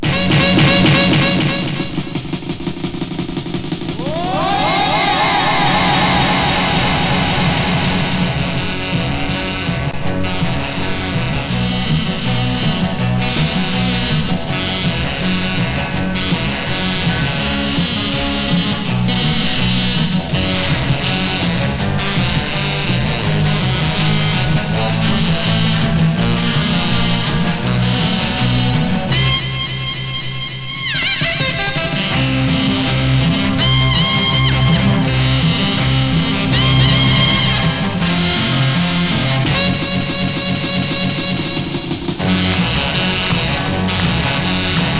Rock & Roll, Surf　USA　12inchレコード　33rpm　Mono